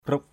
/kru:ʔ/ 1. (d.) min, bò rừng = bœuf sauvage. wild ox. gaur. 2.